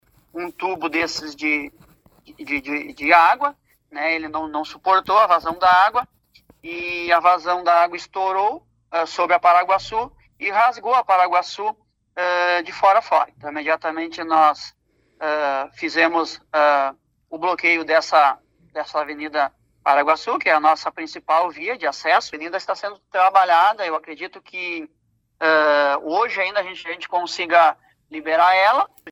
Uma força tarefa atende as emergências e, por enquanto, não há pessoas fora de casa. Quem relata é o secretário de Coordenação de Distritos do município, Itamar Trombetta.